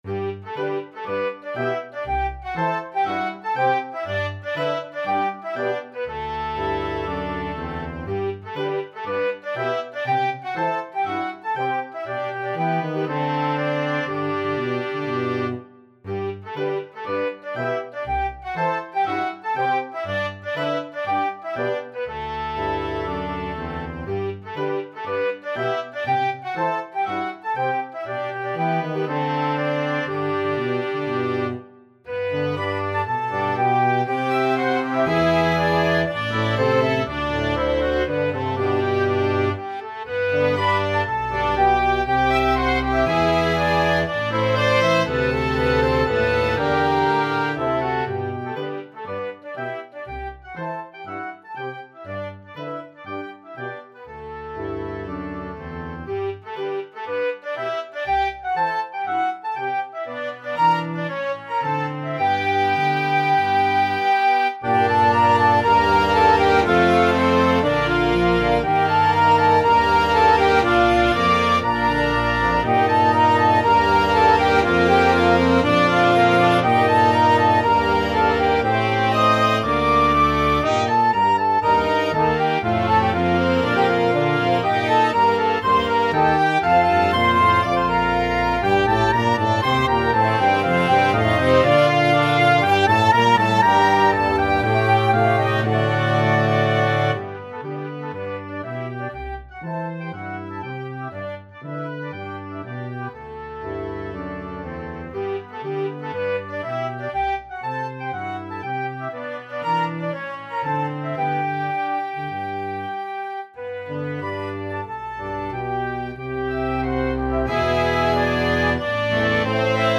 ClarinetFluteOboeTrumpet
EuphoniumTuba
Double Bass
2/4 (View more 2/4 Music)
= 60 Poco lento e grazioso